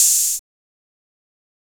Waka HiHat - 1 (4).wav